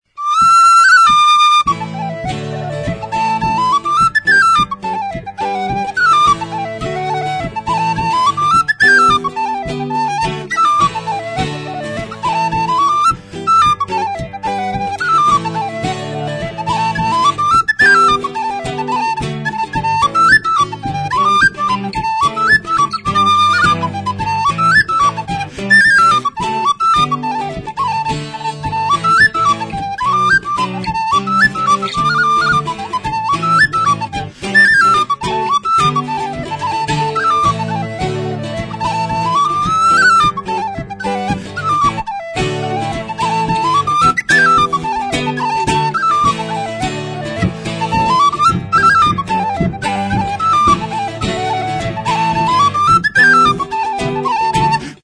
REELS. Turlach (Alan Griffin). HM Udako kontzertua.
WHISTLE; Flauta
Tutu metalikoa eta plastiko urdinezko ahokoa ditu.
Bb tonuan afinaturik dago.